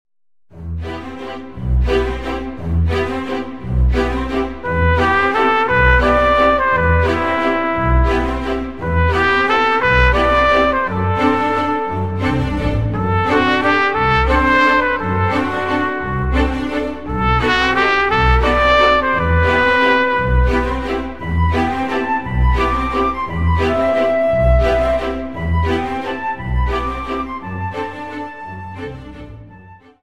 Dance: Viennese Waltz